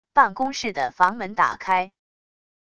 办公室的房门打开wav音频